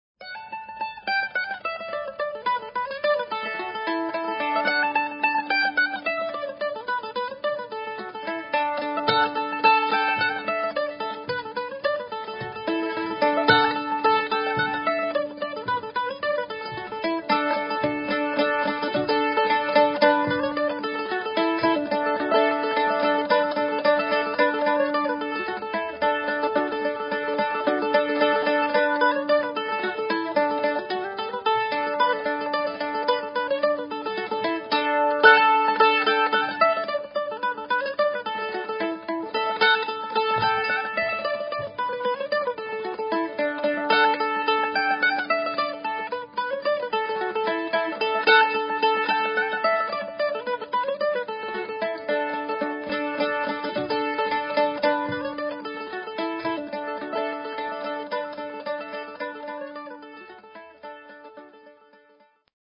recorded live in front of an audience